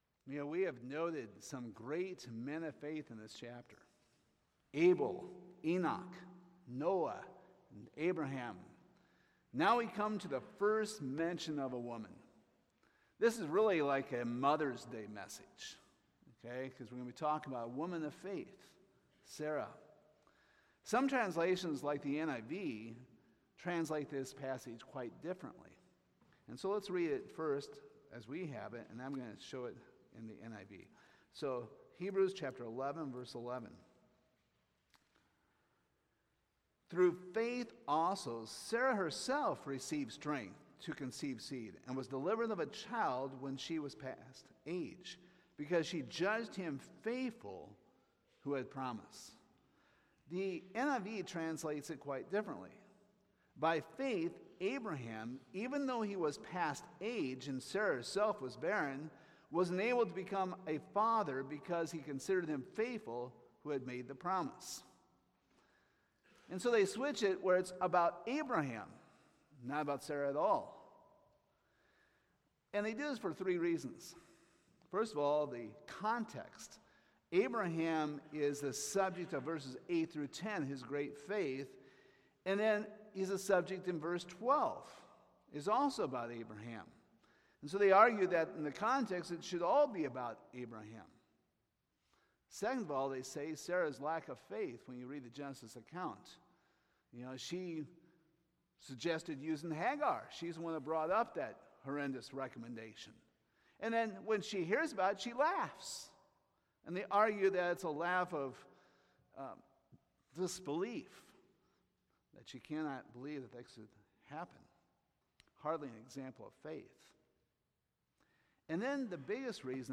Hebrews 11:11-12 Service Type: Sunday Morning What is this portion of the text really about?